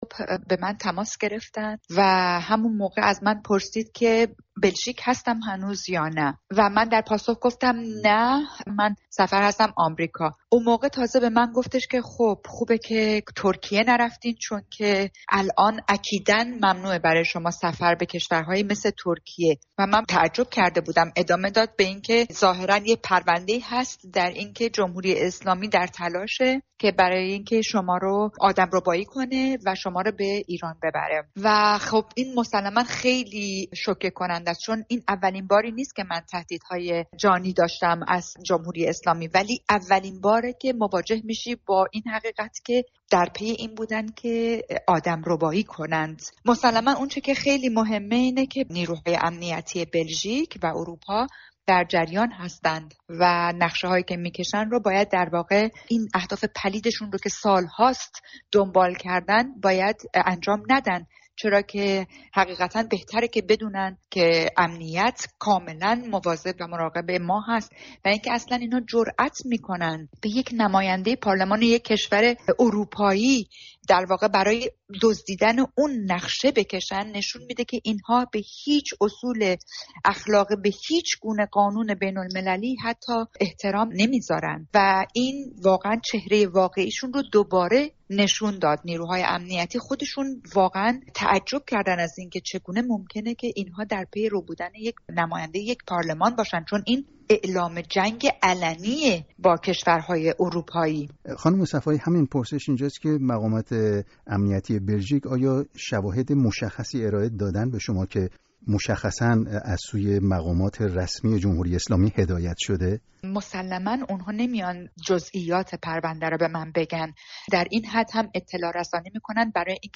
دریا صفایی در گفت‌وگو با رادیو فردا نخست از جزئیات هشدار پلیس بلژیک به او در رابطه با این تهدید می‌گوید.